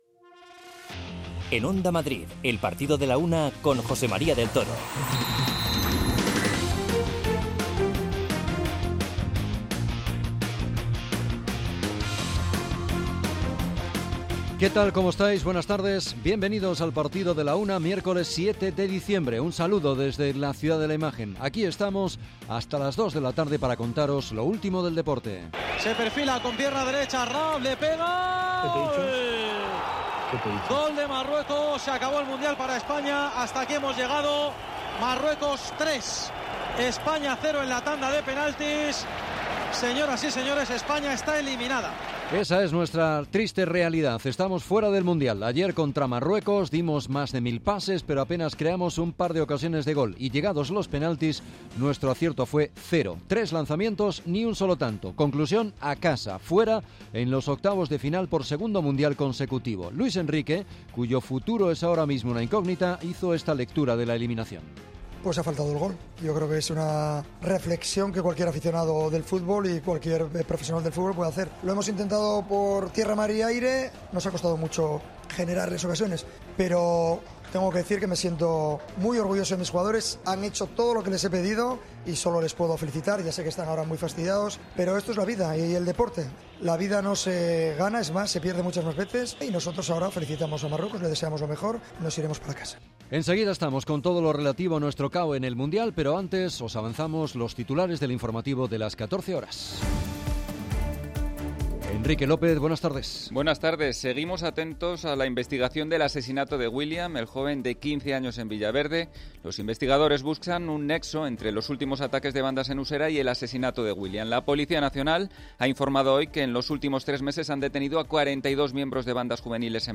España dice adiós al Mundial de Catar al perder frente a Marruecos en los penaltis Escuchamos las reflexiones del seleccionador sobre su futuro y el juego del equipo.